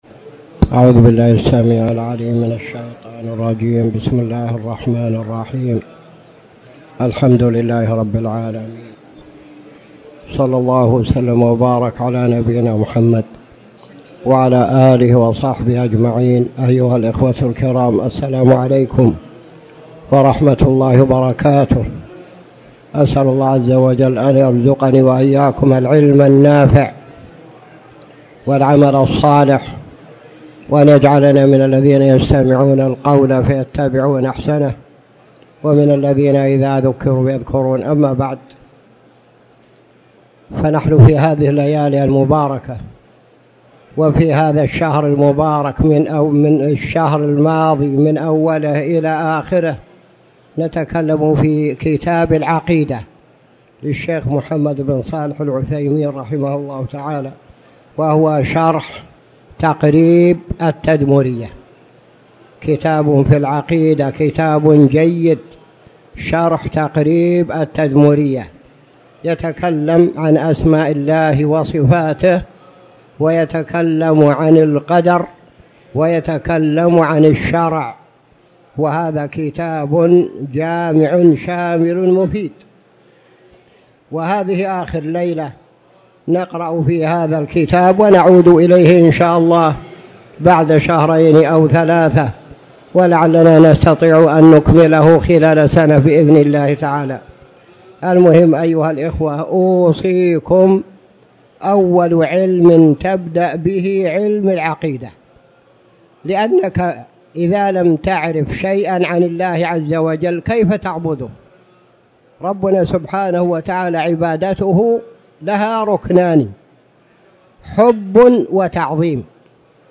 تاريخ النشر ١ ذو الحجة ١٤٣٩ هـ المكان: المسجد الحرام الشيخ